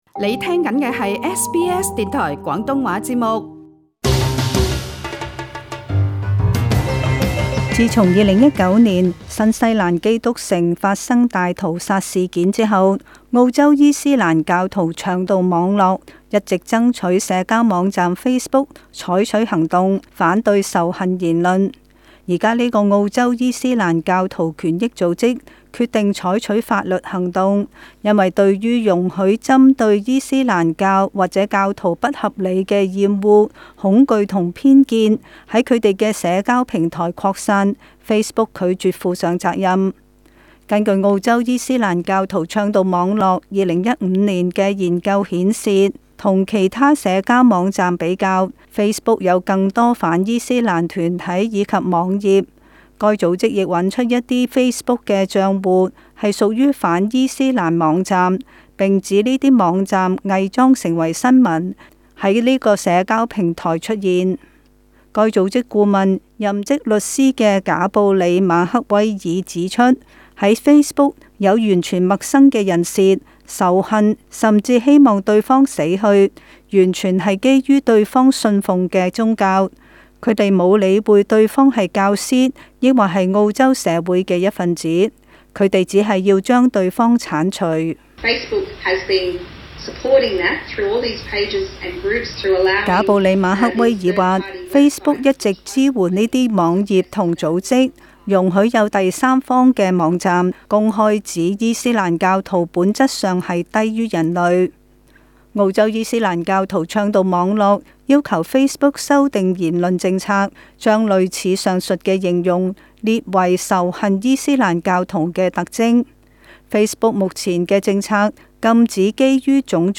【时事报道】